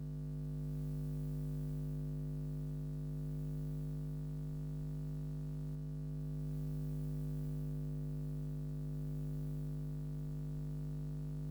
sfx_village_provoda_loop.wav